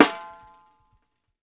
SNARESOFF -R.wav